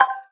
menu_click01.ogg